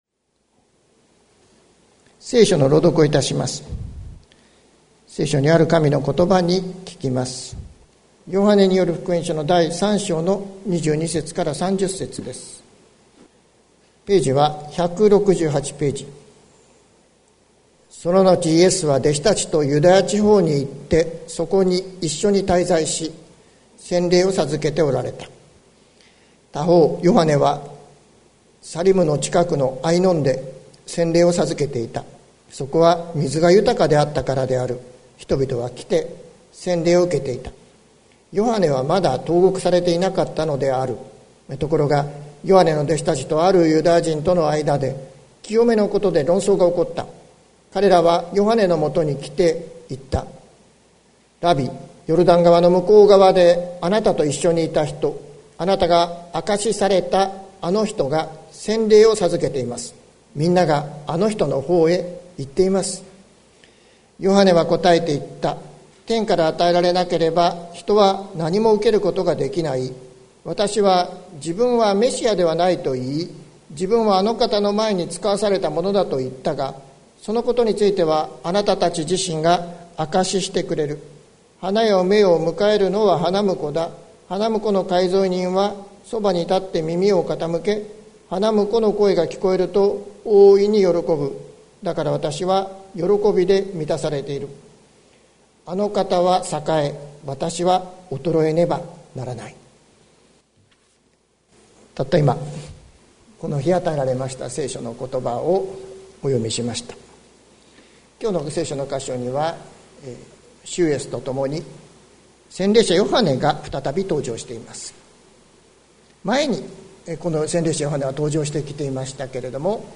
2022年01月09日朝の礼拝「喜びに満ち溢れるために」関キリスト教会
説教アーカイブ。